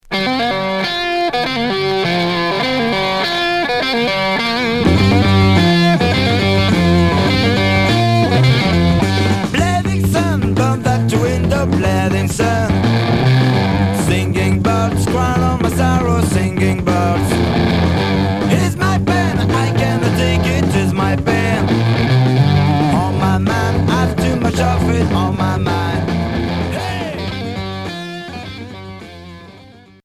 Heavy rock 3ème 45t retour à l'accueil